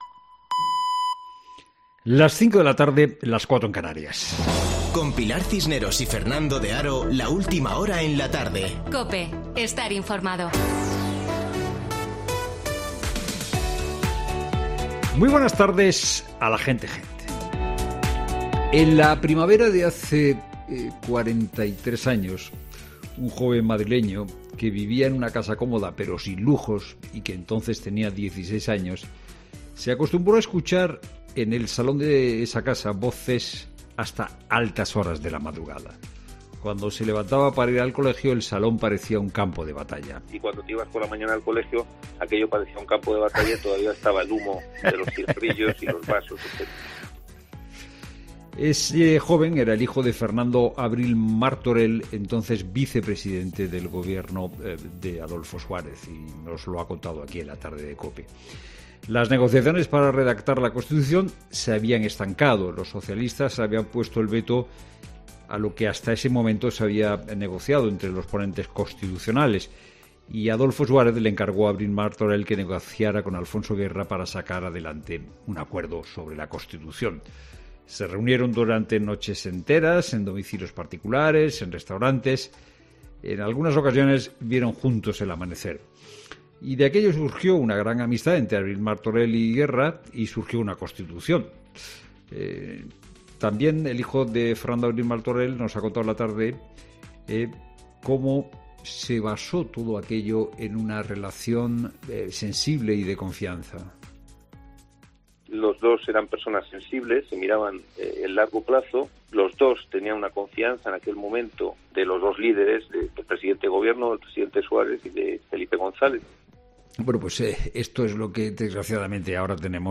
Boletín de noticias COPE del 6 de diciembre de 2021 a las 17.00 horas